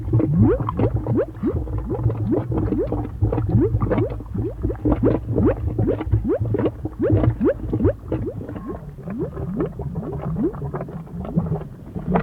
ComedyVolcanoes.L.wav